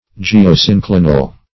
Search Result for " geosynclinal" : The Collaborative International Dictionary of English v.0.48: Geosynclinal \Ge`o*syn*cli"nal\, n. [Gr. ge`a, gh^, the earth + E. synclinal.]